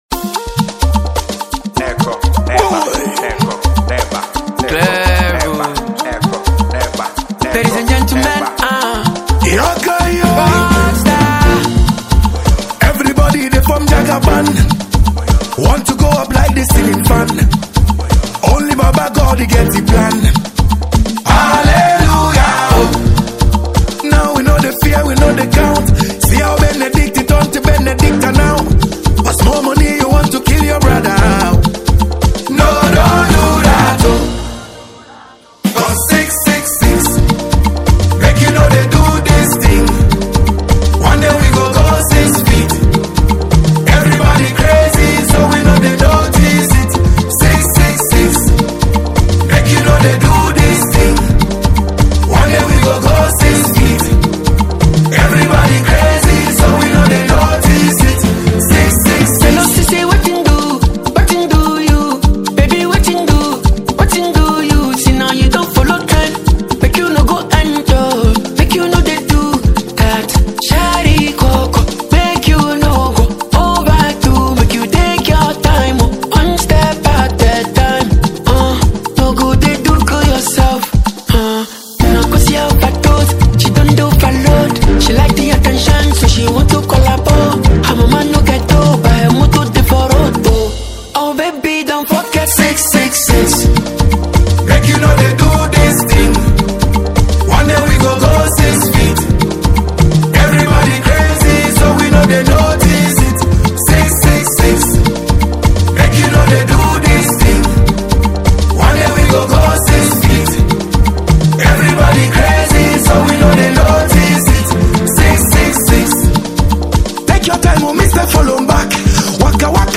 Ghana Music
afrobeats raggae dancehall and highlife musician
an afrobeat Ghanaian singer